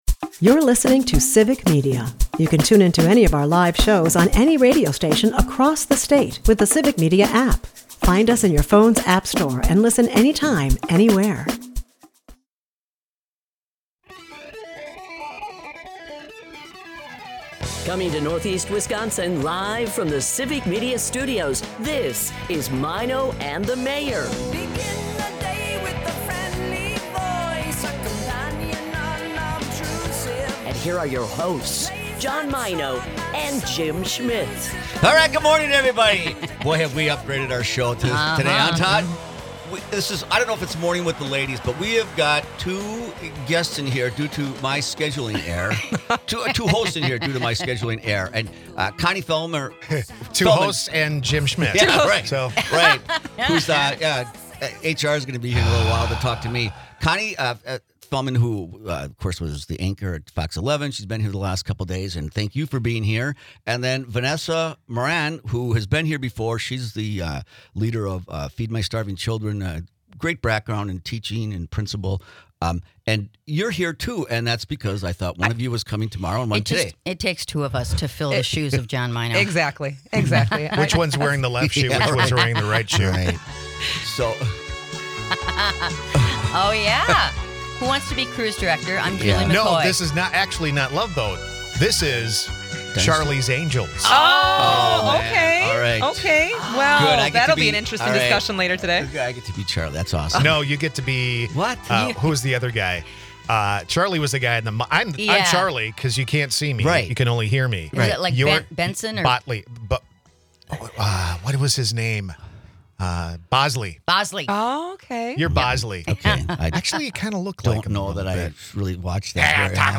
The team navigates an unexpected guest host double booking with humor. The episode features a mix of topics, from a tribute to teachers, to a quirky trivia game centered on Dairy Month. The hosts also touch on culinary adventures, weather updates, and community events, all brimming with community spirit and spontaneous fun.